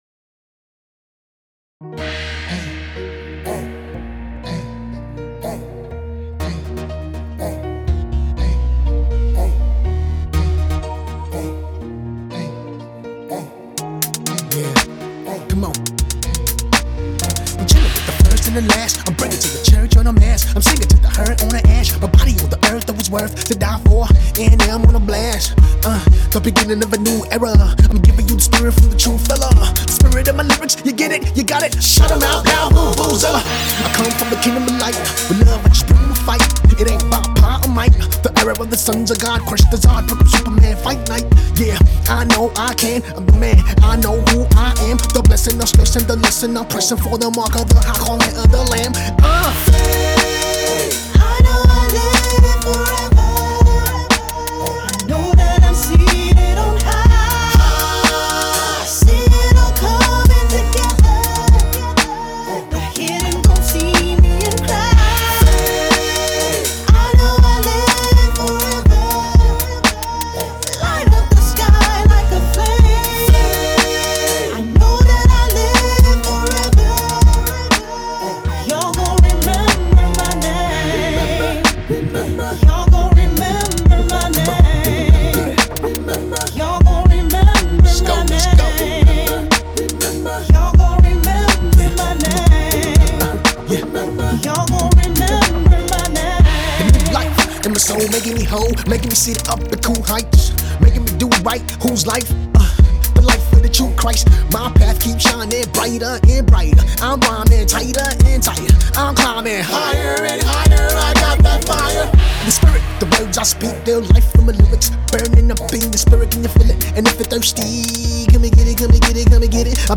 One of Nigeria’s foremost hip-hop acts